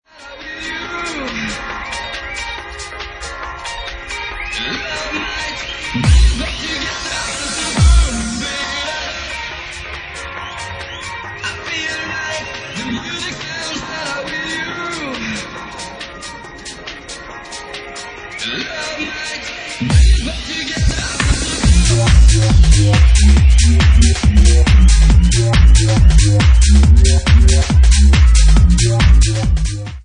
35 bpm